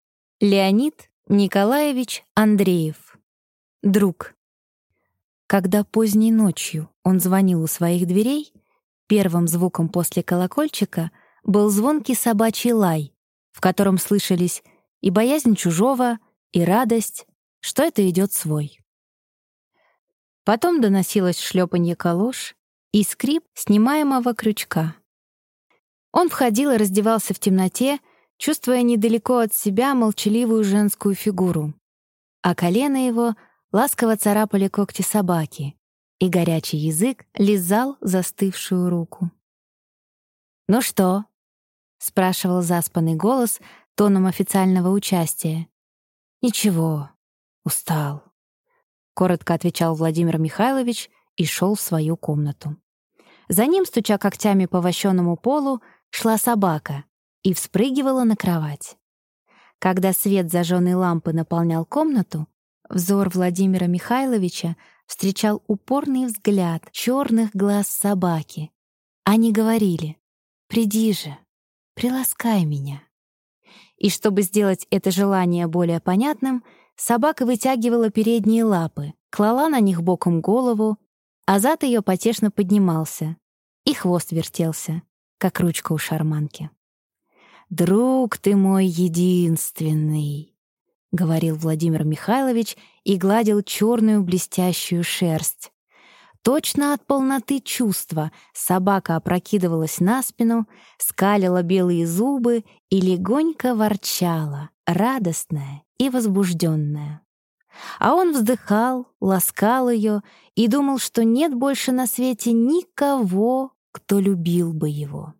Аудиокнига Друг | Библиотека аудиокниг